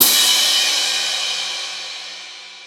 Crashes & Cymbals
Crash SwaggedOut 2.wav